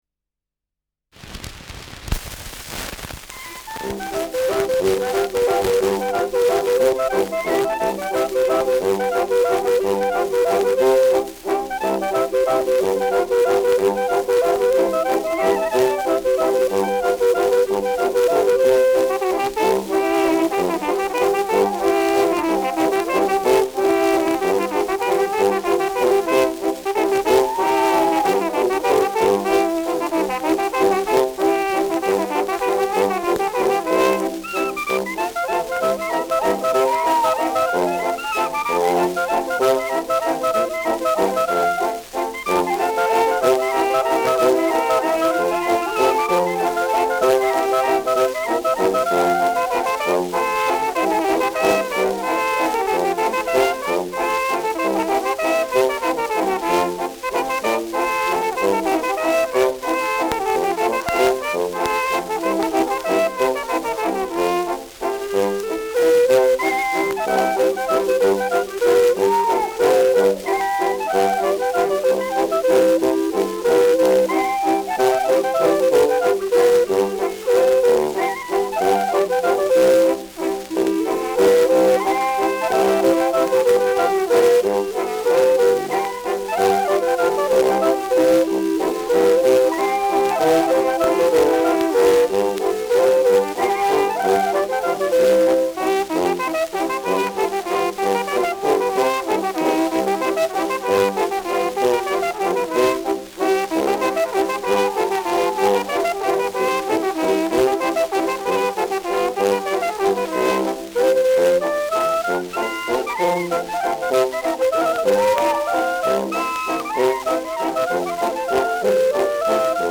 Schellackplatte
Stärkeres Grundrauschen : Verzerrt leicht an lauteren Stellen : Vereinzelt leichtes Knacken
[Nürnberg?] (Aufnahmeort)